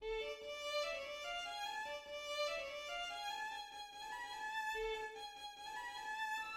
ニ短調 - ニ長調、4分の3拍子、三部形式。
また、ニ長調のトリオへのつなぎでは大胆な転調が行われる。